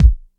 Classic Rap Steel Kick Drum Sample B Key 01.wav
Royality free bass drum sound tuned to the B note. Loudest frequency: 119Hz
classic-rap-steel-kick-drum-sample-b-key-01-2xf.ogg